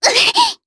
Rodina-Vox_Damage_jp_03.wav